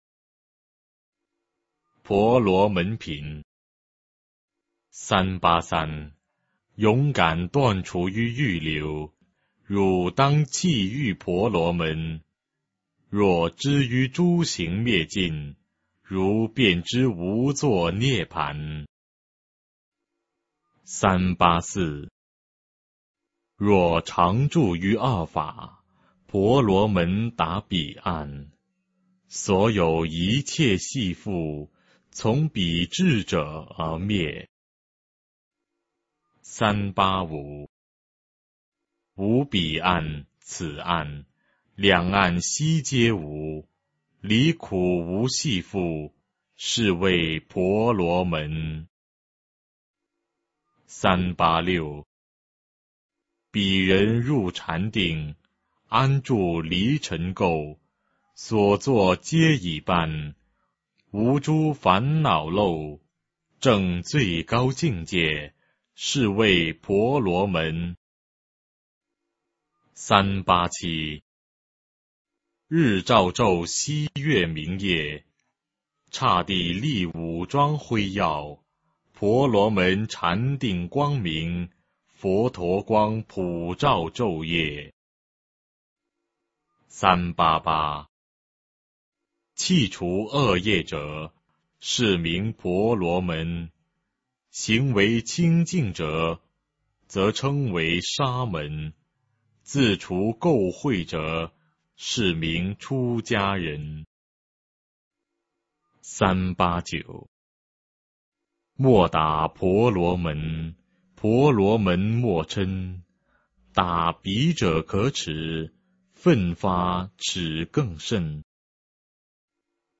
法句经-婆罗门品 - 诵经 - 云佛论坛